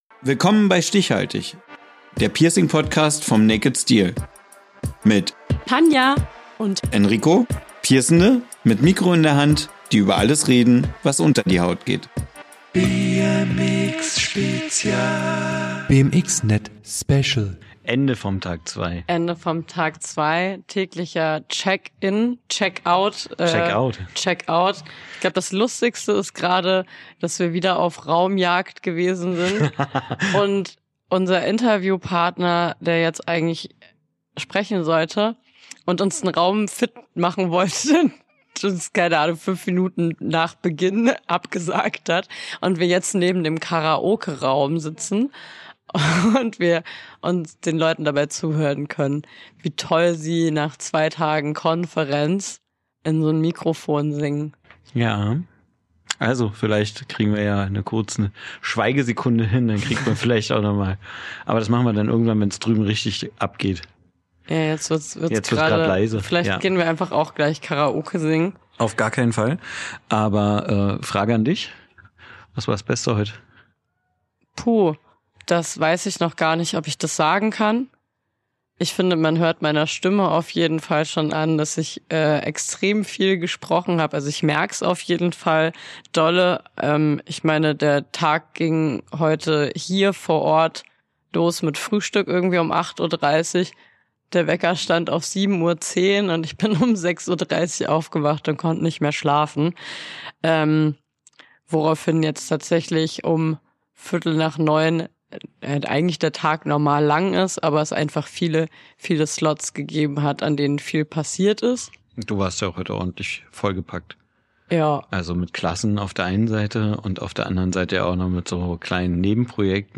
Ohne Interview, nur wir beide